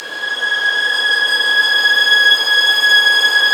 Index of /90_sSampleCDs/Roland LCDP13 String Sections/STR_Violins II/STR_Vls6 p%mf M